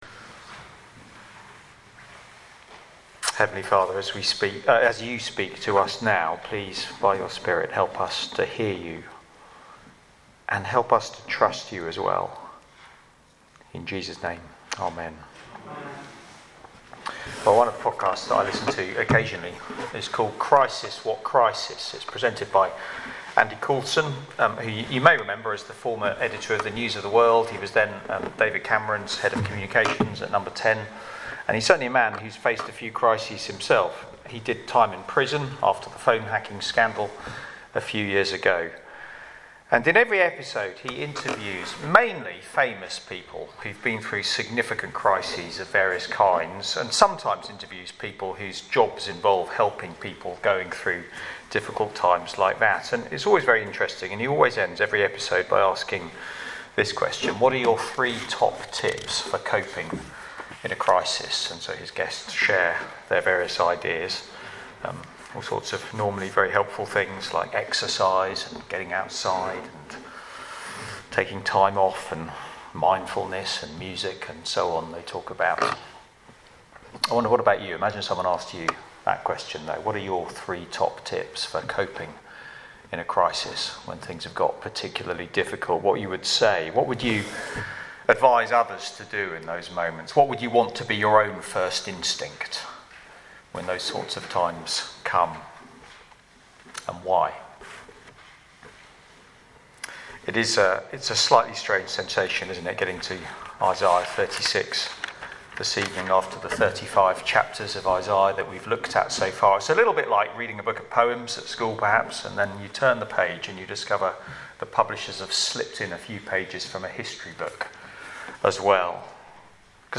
Media for Barkham Morning Service on Sun 05th Feb 2023 10:00
Theme: Sermon